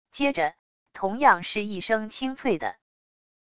接着……同样是一声轻脆的wav音频